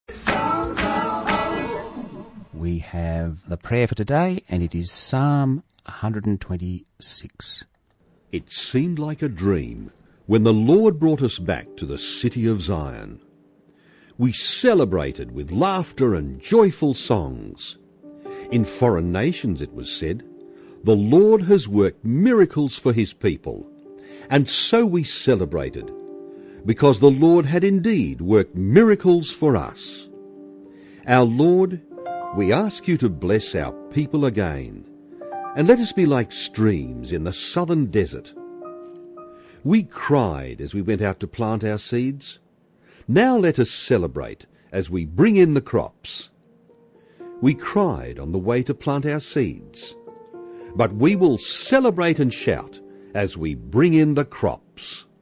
Christian, podcast, prayers